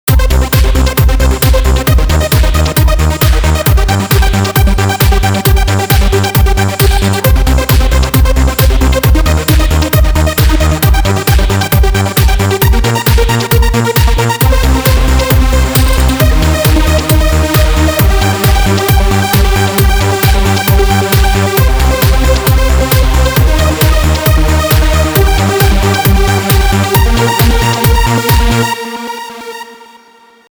Also trance arpeggios well known from the late 1990s can be created very easily.
Select an unisono saw sound with much chorus and much delay Fx on your synthesizer.
A second track should play a simple bassline.
Finally start the sequencer, and play only single notes on your MIDI keyboard.
(drums are based on sample loops)
Sidenote: in the second half of the MP3 you will hear the difference of a 100% gate length compared to ca. 50% gate length at the beginning.